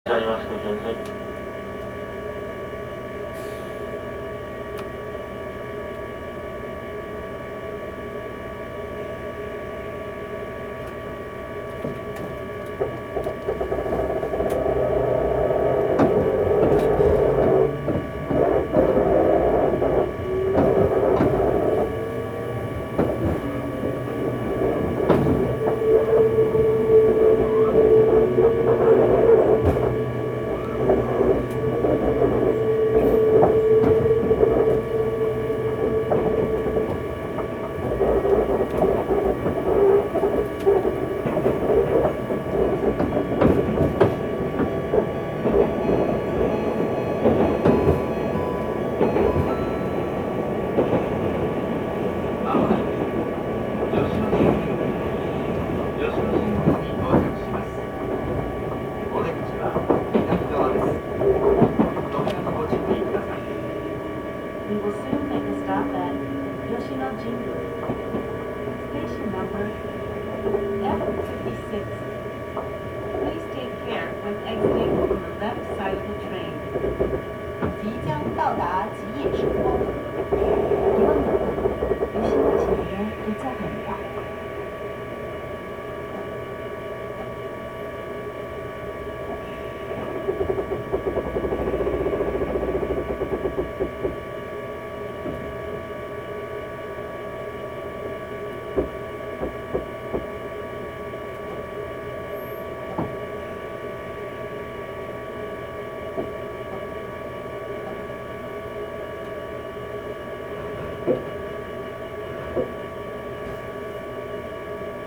走行音
録音区間：大和上市～吉野神宮(さくらライナー)(お持ち帰り)